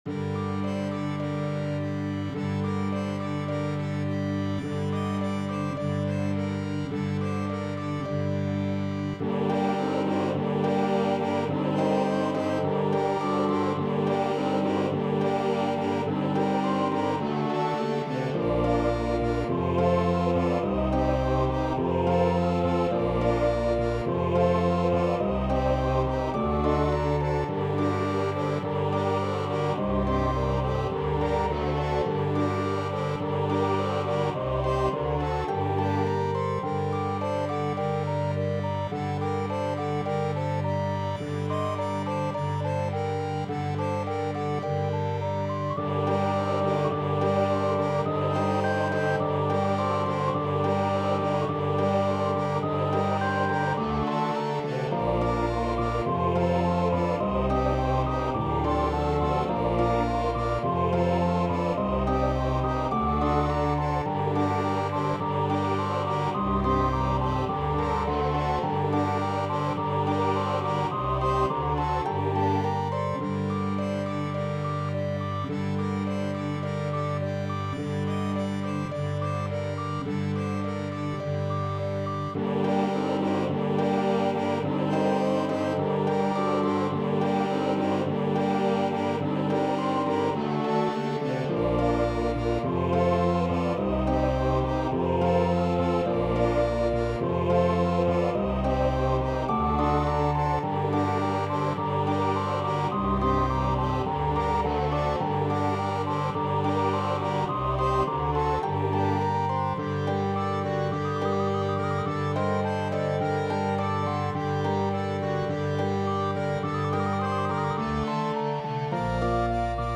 Chantez en chœur
• Le chant traditionnel breton